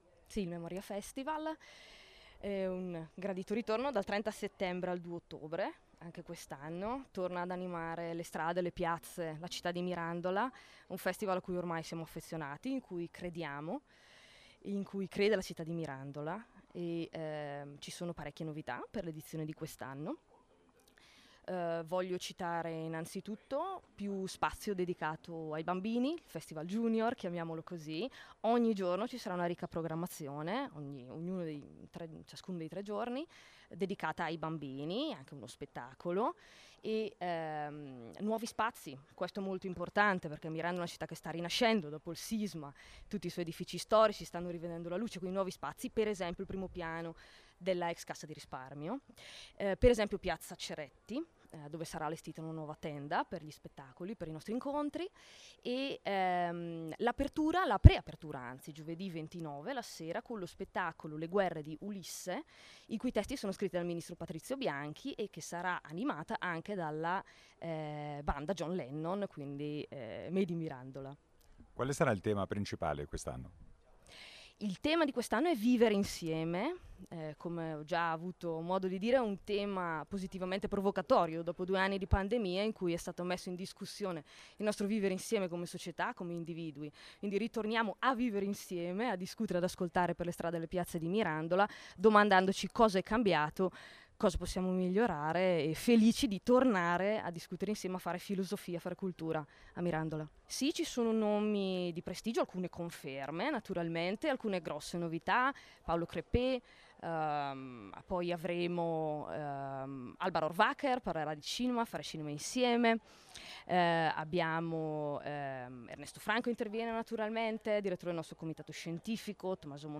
Ecco le dichiarazioni raccolte durante la giornata di presentazione: